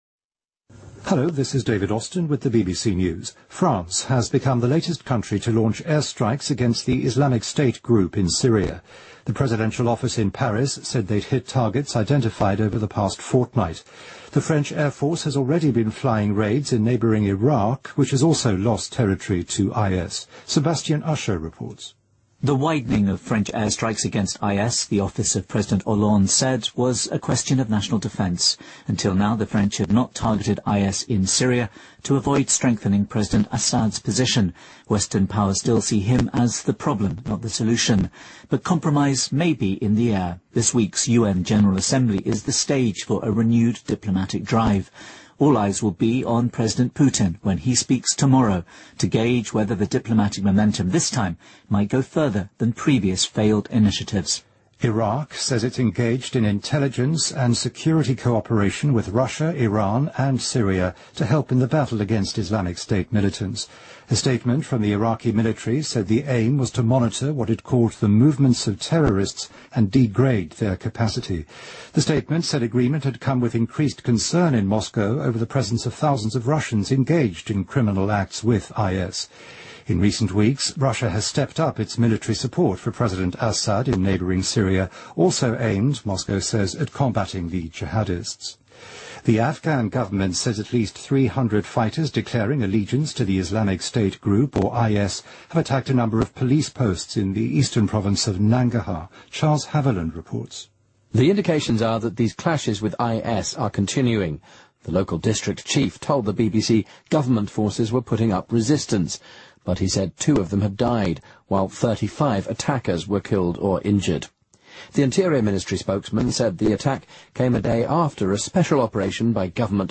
BBC news,法国战机首次空袭叙利亚极端组织